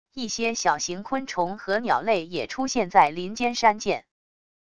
一些小型昆虫和鸟类也出现在林间山涧wav音频